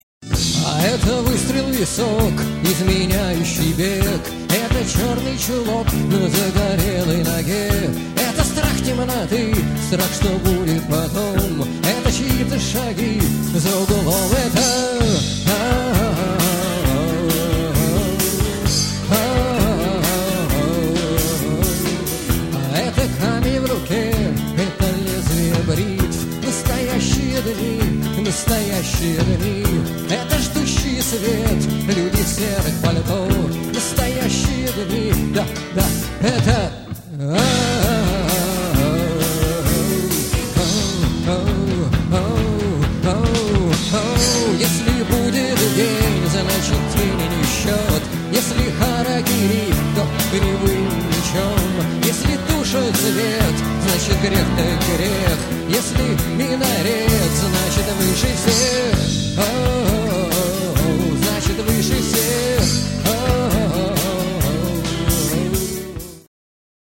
Шаболовка (1993)
AUDIO, stereo